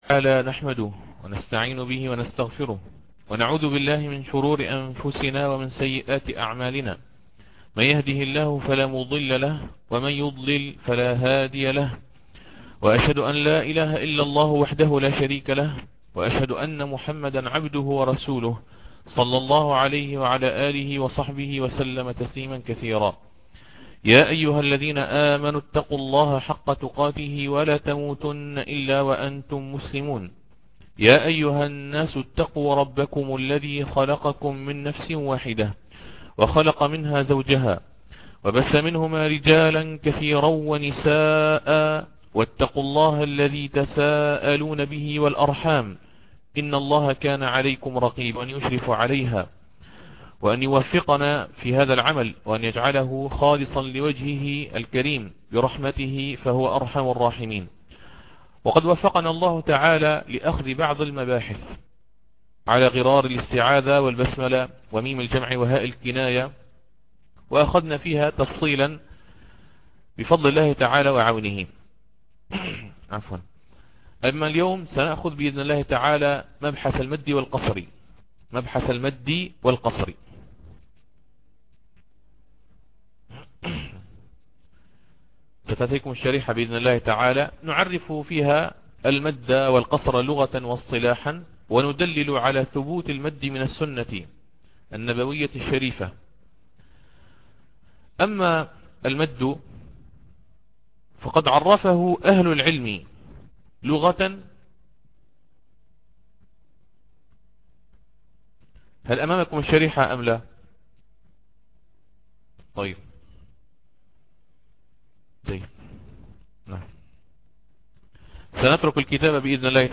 تسجيل الدرس الرابع من أصول قالون.mp3